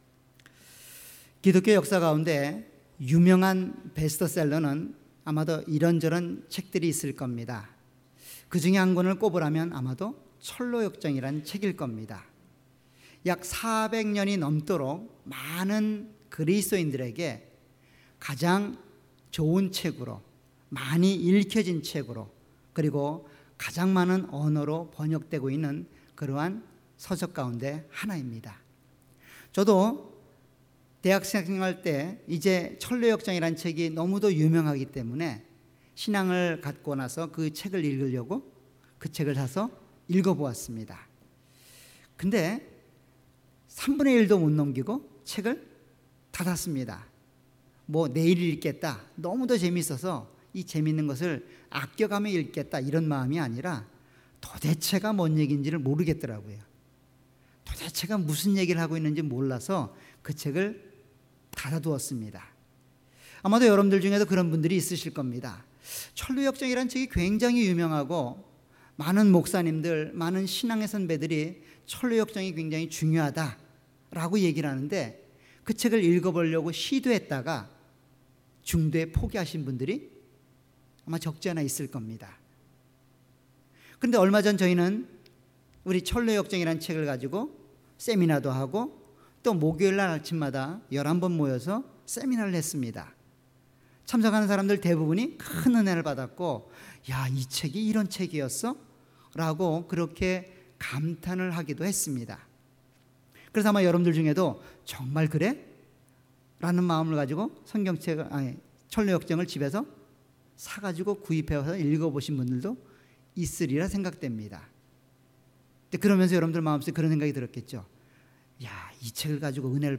All Sermons
Series: 주일예배.Sunday